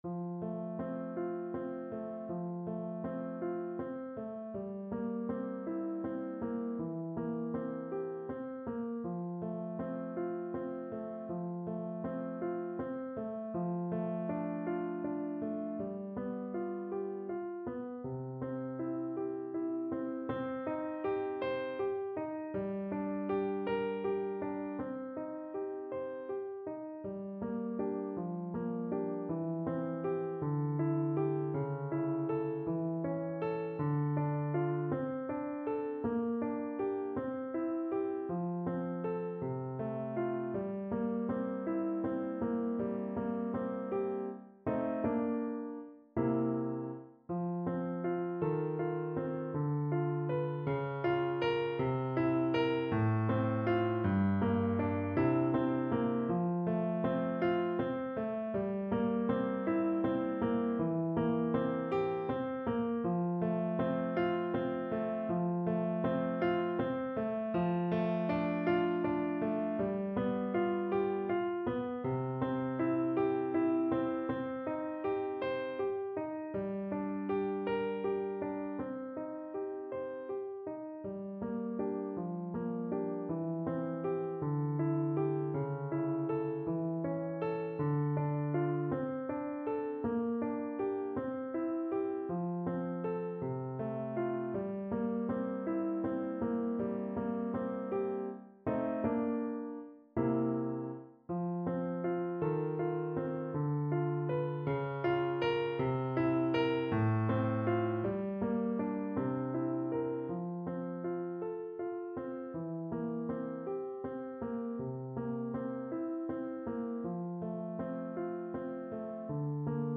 Mendelssohn-Bartholdy: Na skrzydłach pieśni (na klarnet i fortepian)
Symulacja akompaniamentu